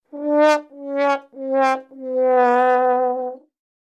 Уставший трамбон